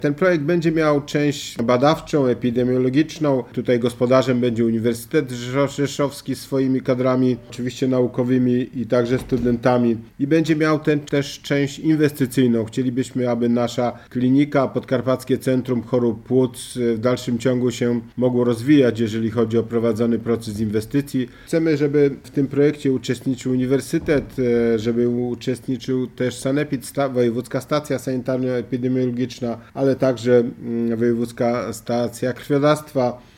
Mówi marszałek podkarpacki Władysław Ortyl: